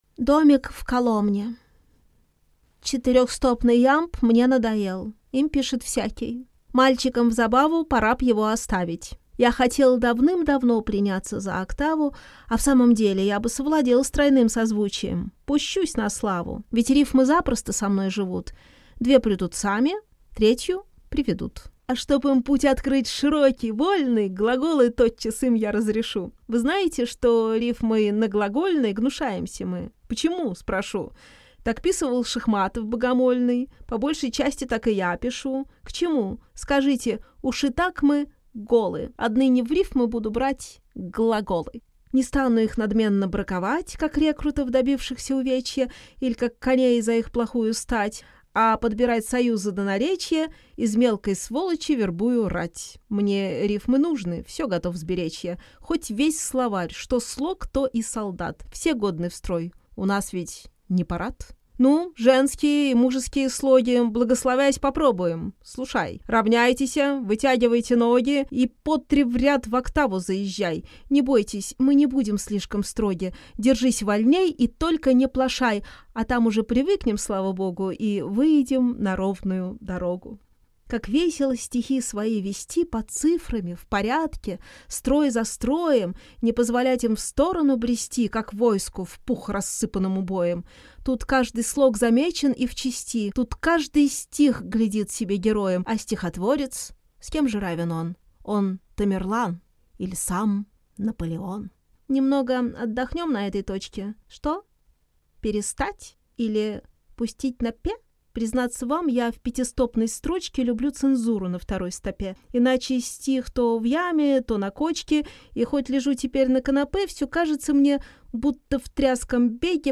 Аудиокнига Домик в Коломне | Библиотека аудиокниг
Прослушать и бесплатно скачать фрагмент аудиокниги